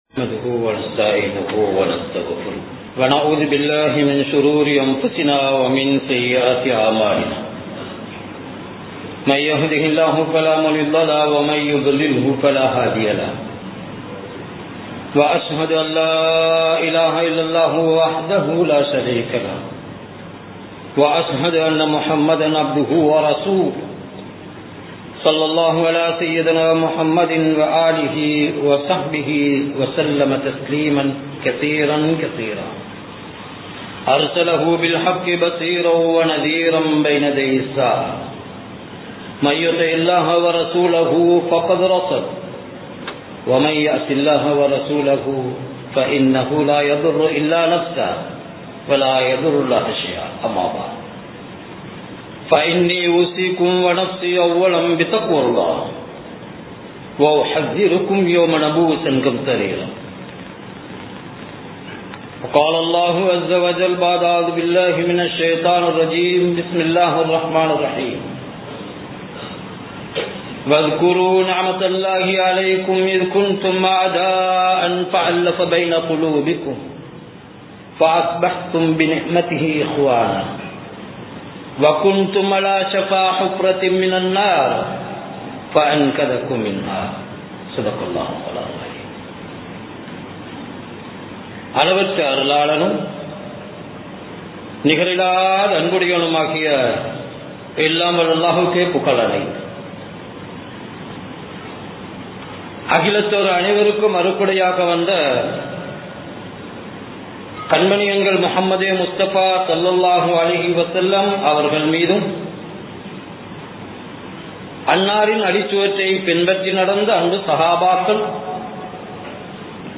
இன்றைய நிலைமையும் அதற்கான தீர்வுகளும் | Audio Bayans | All Ceylon Muslim Youth Community | Addalaichenai
Colombo 03, Kollupitty Jumua Masjith